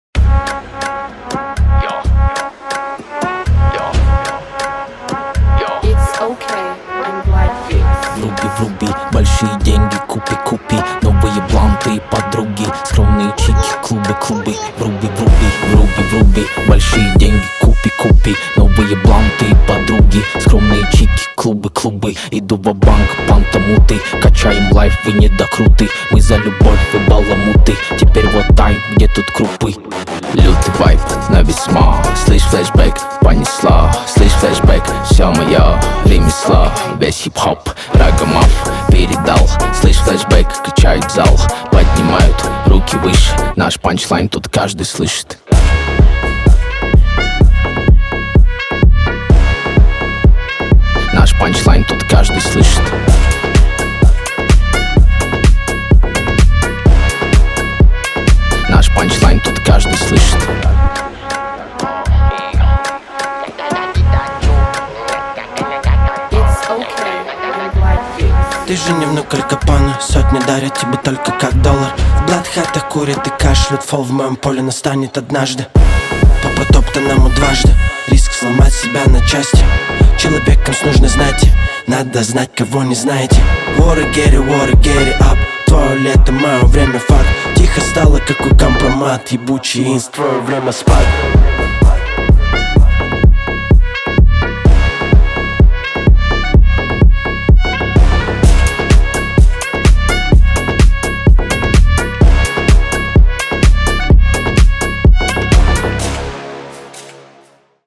Рэп, Новинки музыки в пятницу